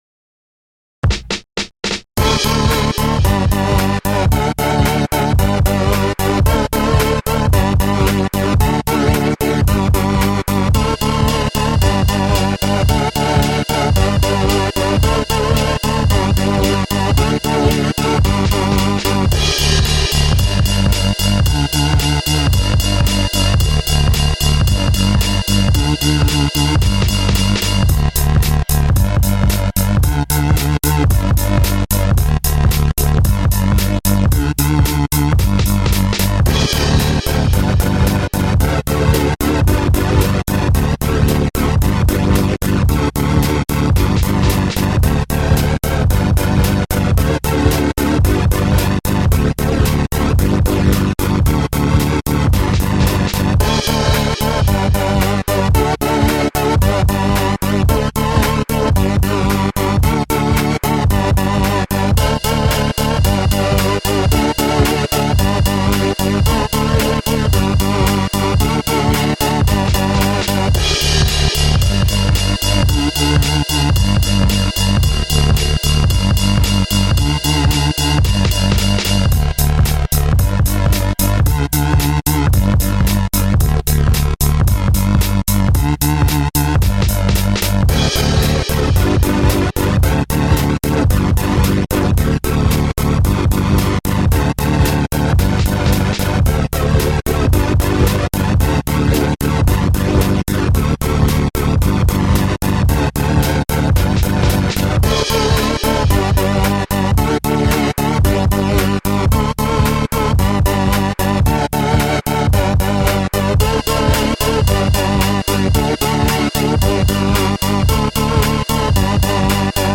This sounds like someone should be rapping over it or maybe playing a solo I dunno.